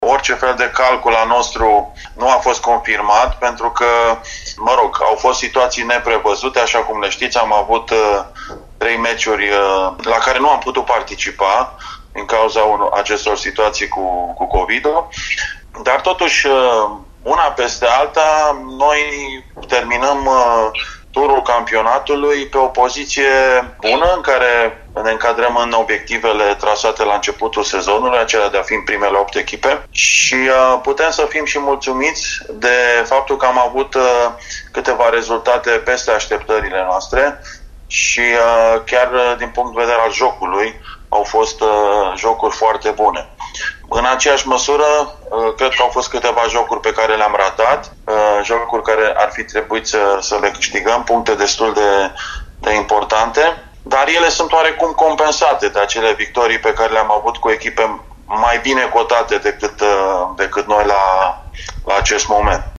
a vorbit astăzi, la Arena Radio, despre fluctuațiile din prima jumătate a sezonului regular: